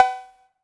Perc [ Pick Up The Phone ].wav